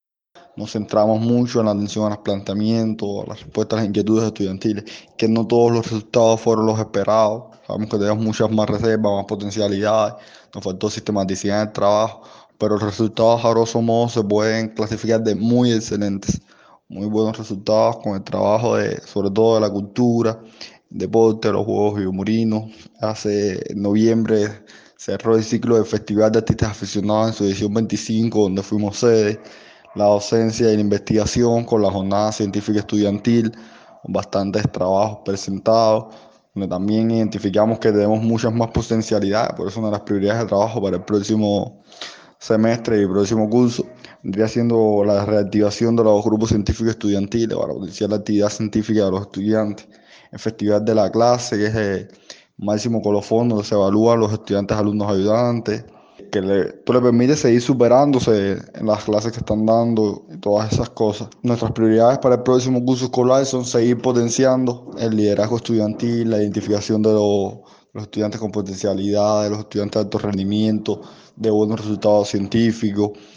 El martes último, en la sede Camilo Cienfuegos de la Universidad de Matanzas, se celebró el Consejo Ampliado de la Federación Estudiantil Universitaria (FEU) matancera, a sólo días de celebrar el 101 aniversario de la organización joven más antigua de Cuba.